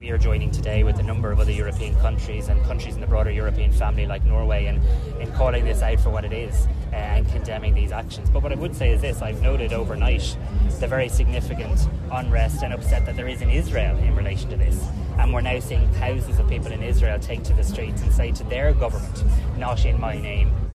Speaking today at the Tullamore Show in County Offaly, Simon Harris says they’re calling for an immediate ceasefire – the release of hostages, and the large-scale entry of humanitarian aid: